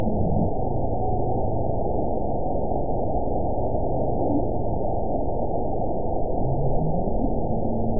event 922135 date 12/27/24 time 03:23:16 GMT (5 months, 3 weeks ago) score 5.97 location TSS-AB04 detected by nrw target species NRW annotations +NRW Spectrogram: Frequency (kHz) vs. Time (s) audio not available .wav